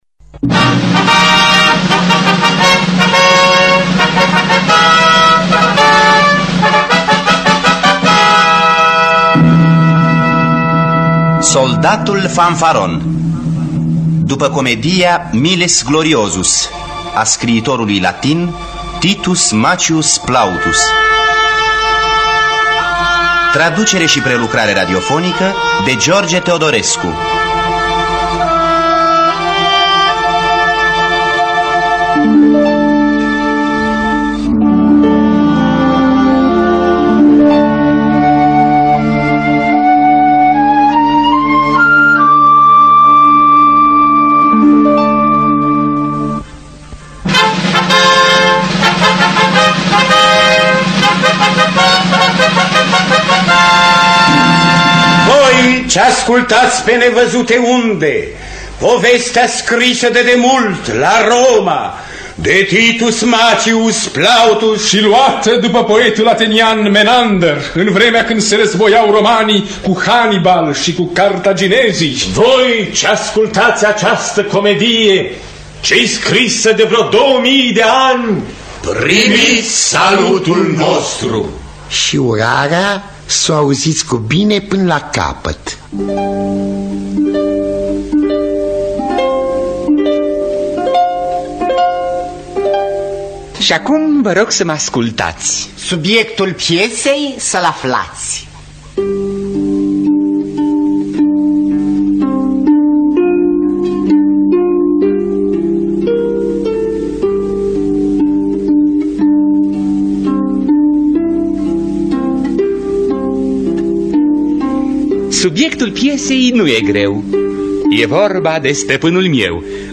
Titus Maccius Plautus – Soldatul Fanfaron (1954) – Teatru Radiofonic Online